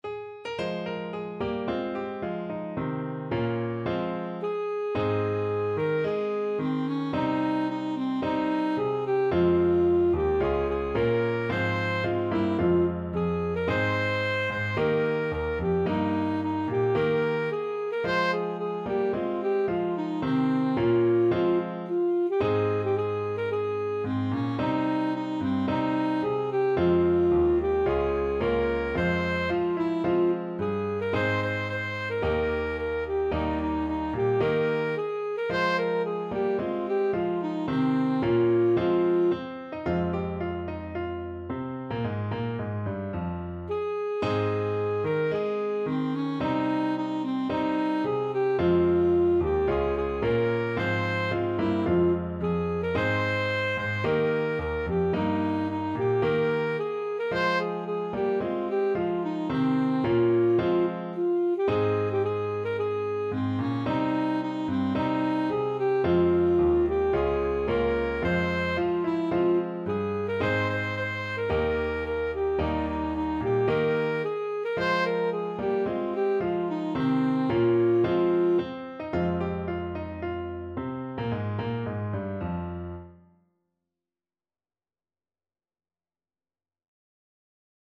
Alto Saxophone version
Alto Saxophone
~ = 110 Allegro (View more music marked Allegro)
4/4 (View more 4/4 Music)
Traditional (View more Traditional Saxophone Music)